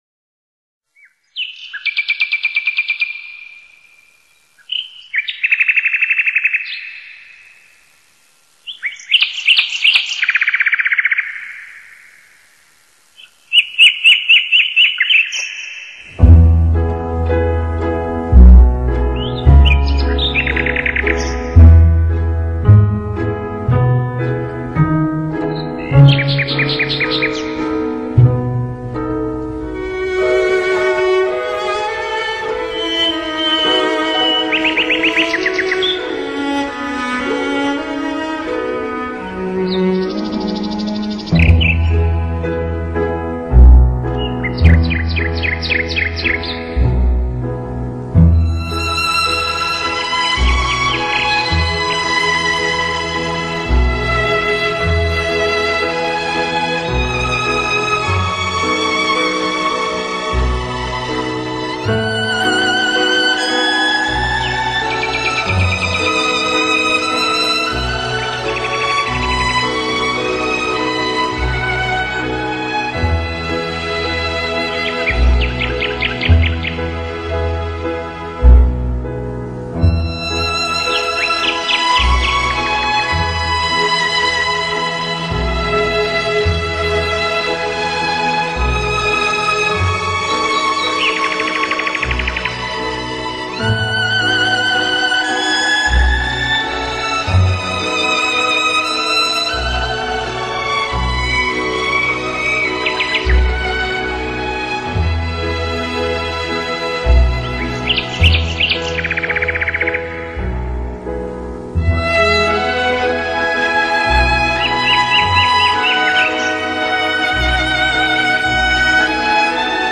canto-dos-passaros.mp3